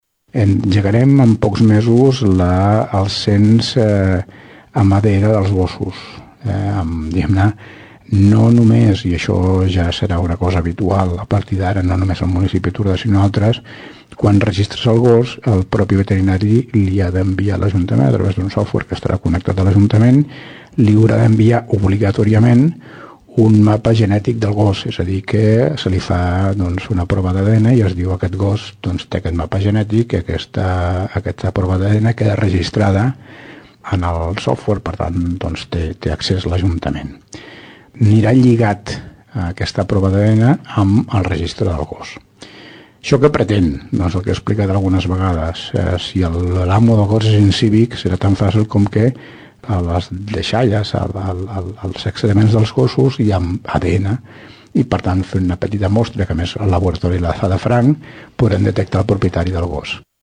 Explicava el procediment l’ alcalde de Tordera, Joan Carles Garcia.